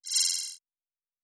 pgs/Assets/Audio/Sci-Fi Sounds/Interface/Data 06.wav at master